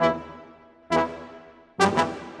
Rock-Pop 20 Trombone _ Tuba 04.wav